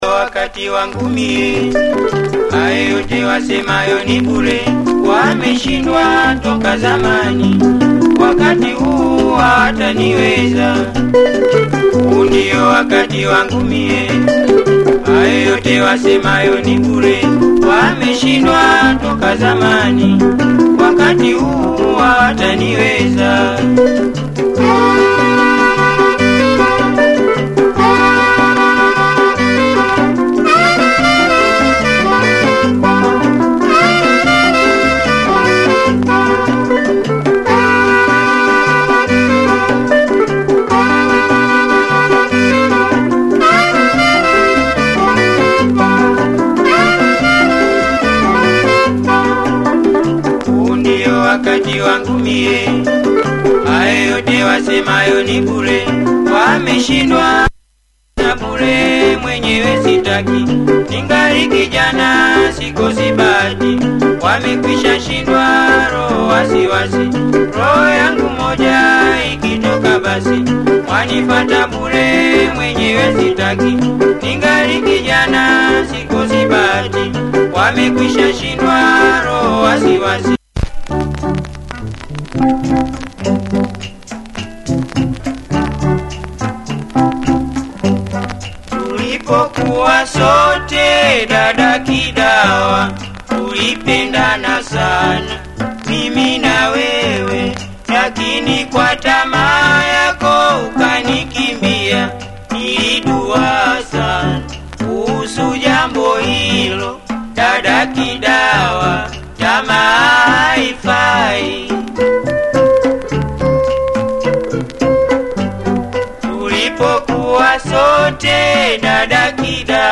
Has some usage wear but plays well.